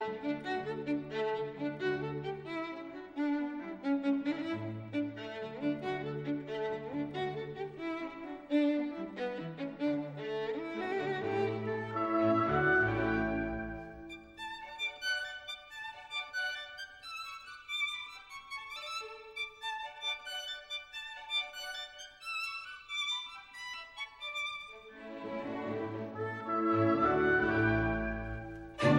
vanessa-mae-beethoven-violin-concerto-in-d-op-61-iii-rondo-allegro.mp3